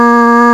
Index of /m8-backup/M8/Samples/Fairlight CMI/IIX/CHORAL
BASS2.WAV